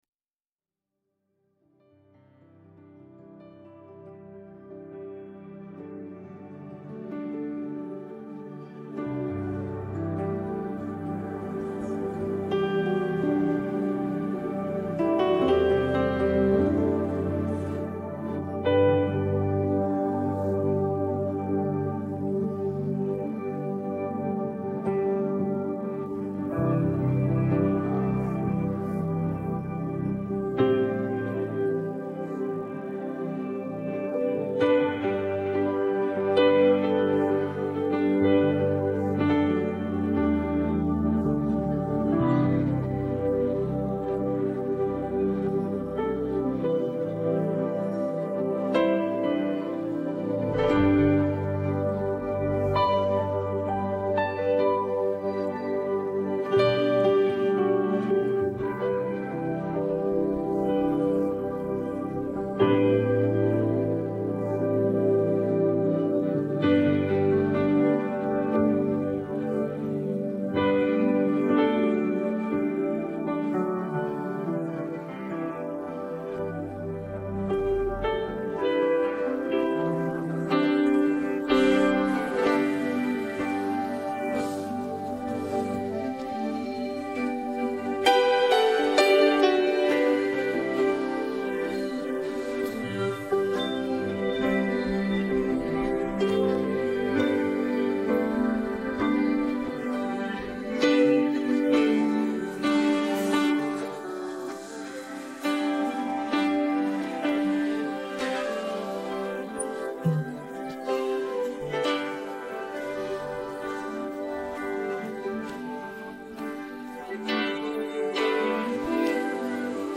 Download Piano Track